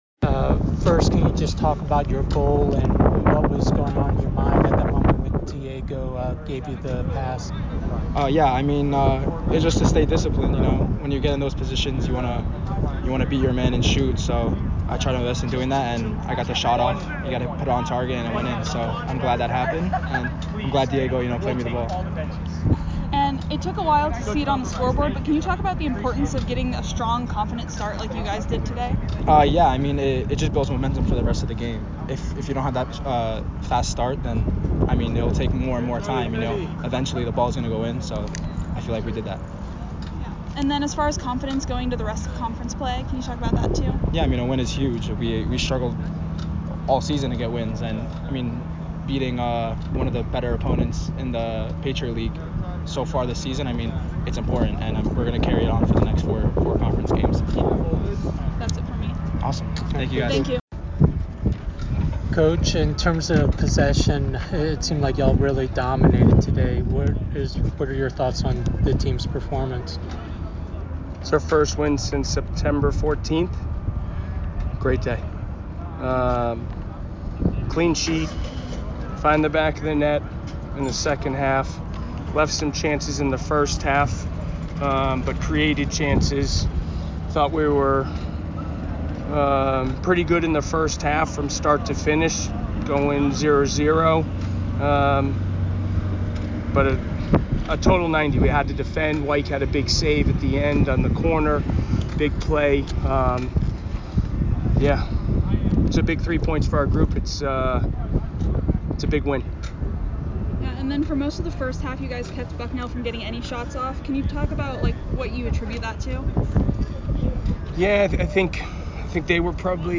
Men's Soccer / Bucknell Postgame Interview (10-12-24)